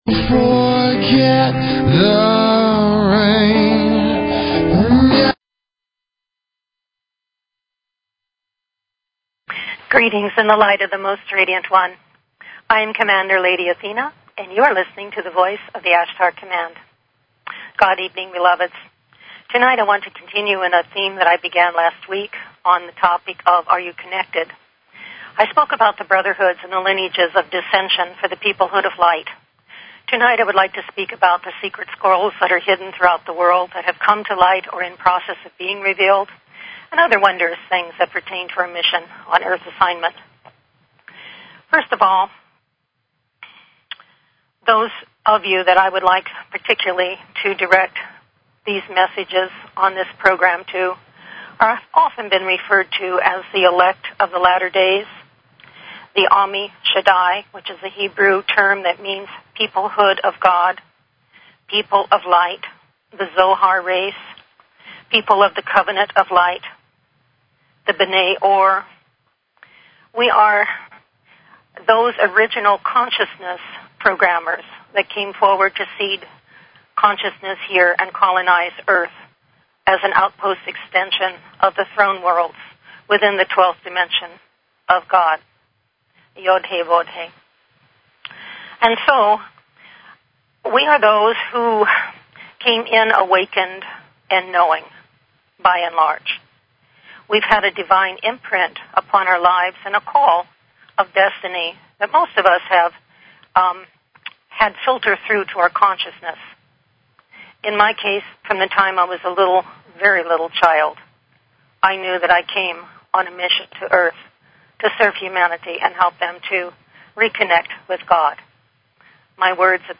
Courtesy of BBS Radio
Talk Show